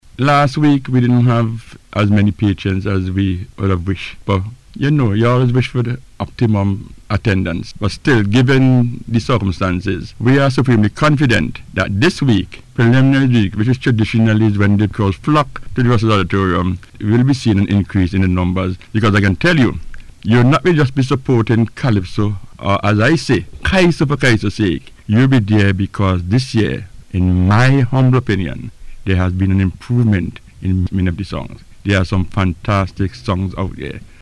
Speaking on NBC’S the Talk Yuh Talk programme